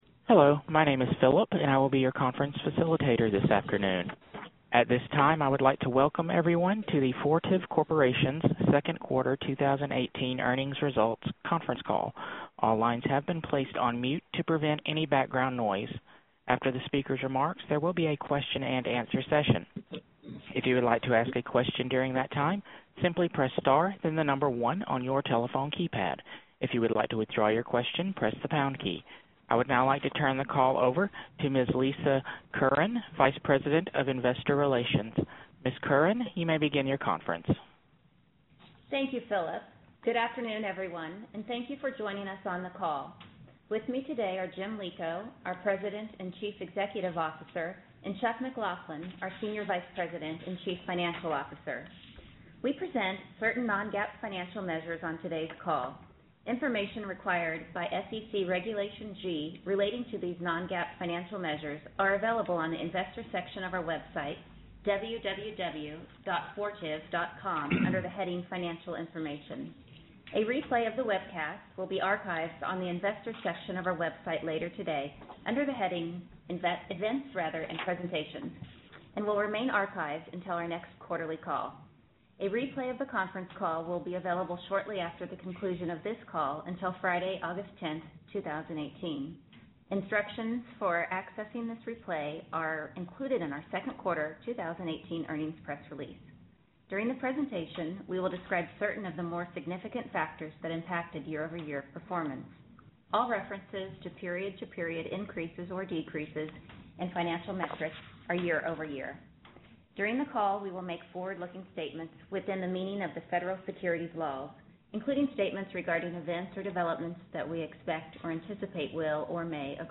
FTV_2Q18_Earnings_Call_Replay.mp3